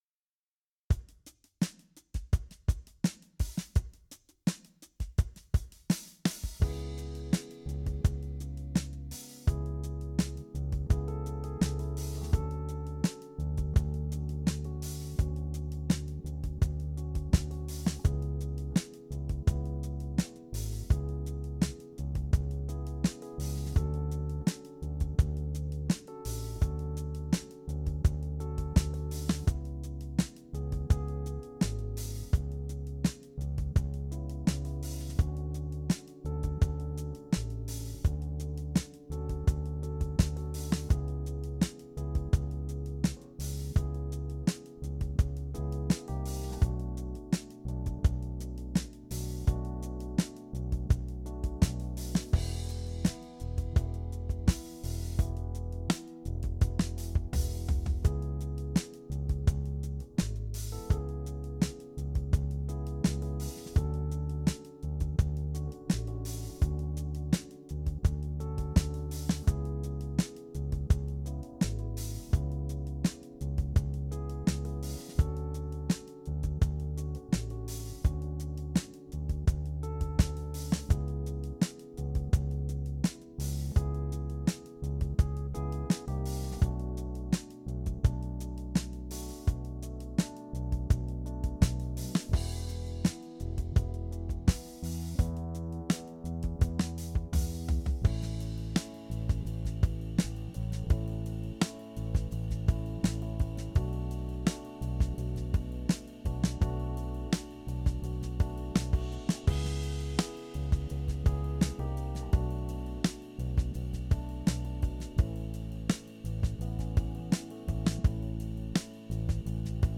Jam Track